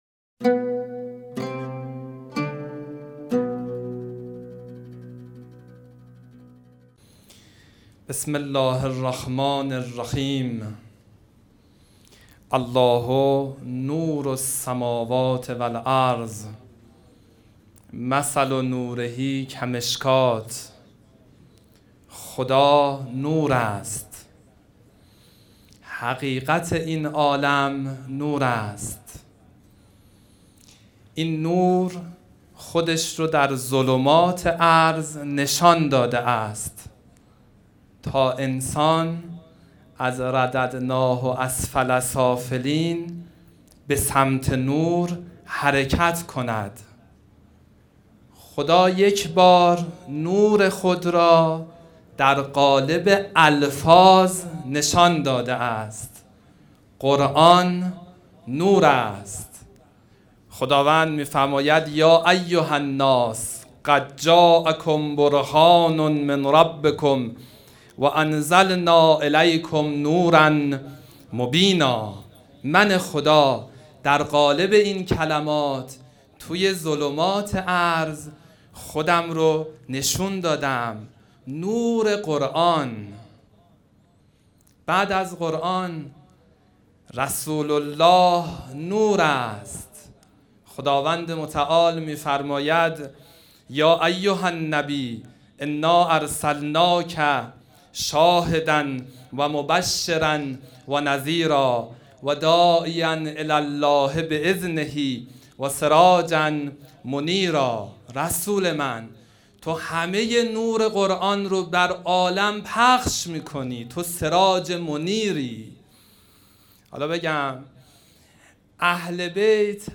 سخنرانی
ششمین همایش هیأت‌های محوری و برگزیده کشور | شهر مقدس قم - مجتمع یاوران مهدی (عج)